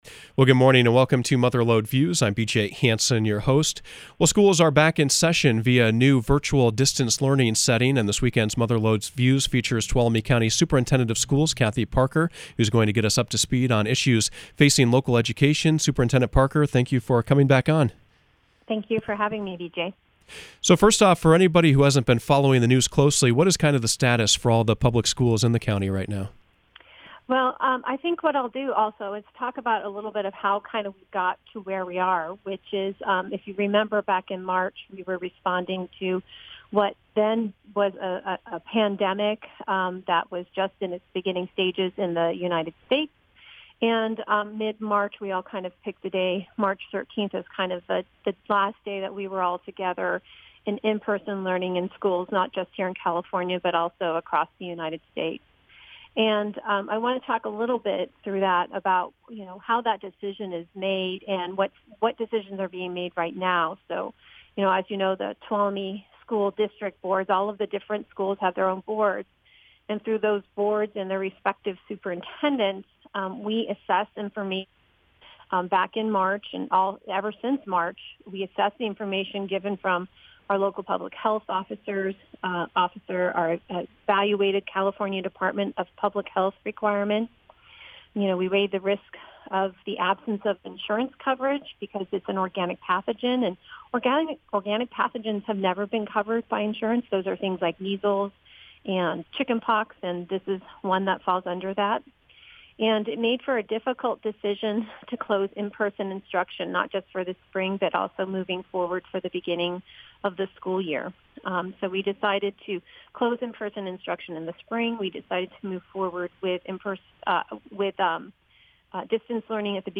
Mother Lode Views featured Tuolumne County Superintendent of Schools Cathy Parker.